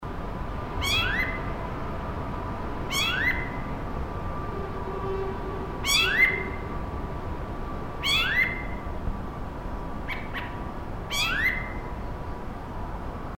Zorzal Colorado (Turdus rufiventris)
Nombre en inglés: Rufous-bellied Thrush
Provincia / Departamento: Ciudad Autónoma de Buenos Aires
Condición: Silvestre
Certeza: Vocalización Grabada